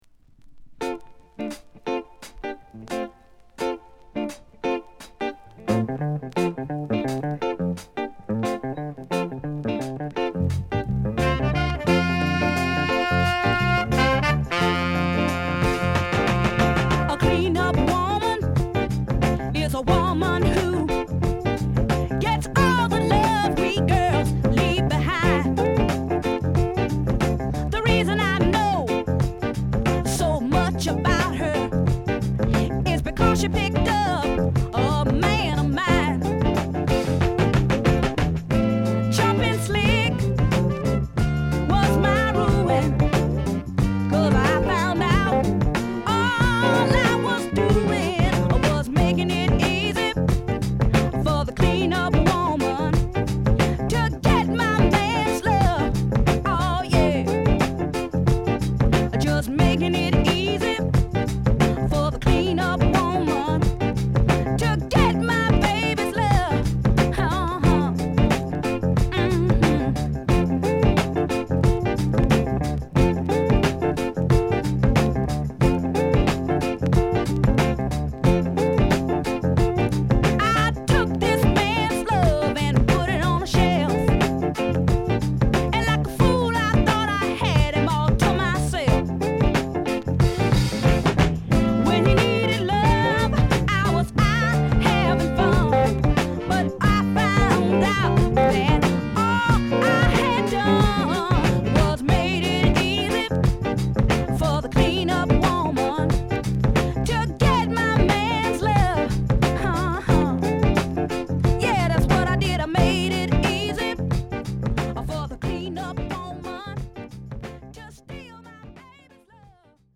プレイは良好です。